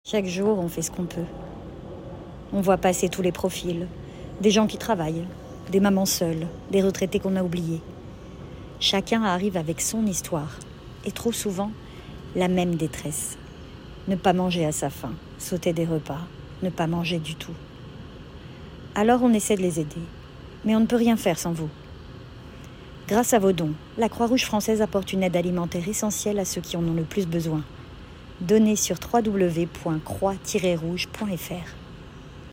Voix off
25 - 36 ans - Mezzo-soprano Soprano